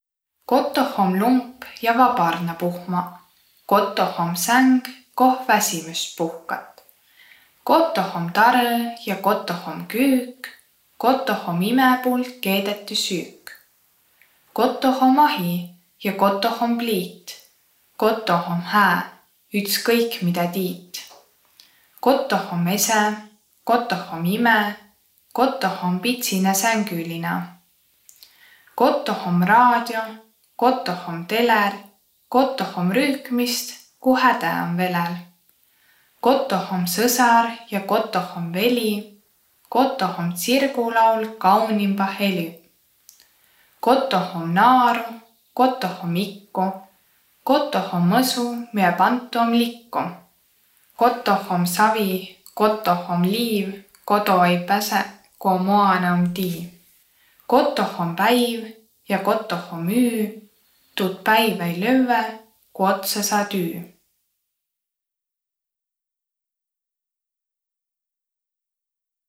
Luulõtus “Kotoh”
Peri plaadilt juttõ ja laulõ seto aabitsa mano.